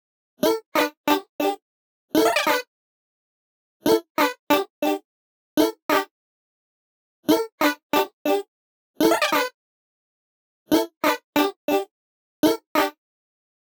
簡単にいうと音を「少し揺らして」広がりを与えてくれるエフェクターです。
Vocal Doubler onの音源
その名の通り、音がダブって聴こえるようにしてくれるエフェクターとなっています。